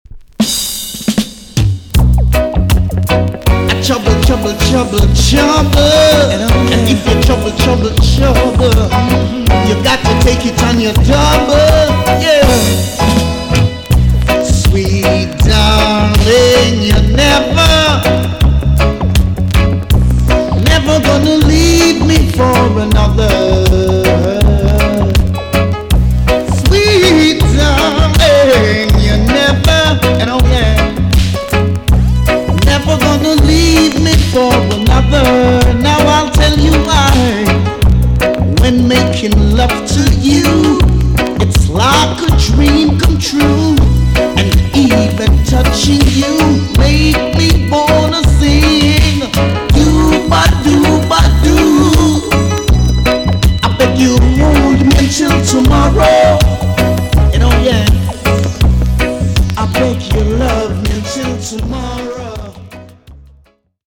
TOP >DISCO45 >80'S 90'S DANCEHALL
EX-~VG+ 少し軽いチリノイズが入りますが良好です。
1986 , NICE VOCAL TUNE!!